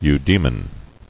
[eu·dae·mon]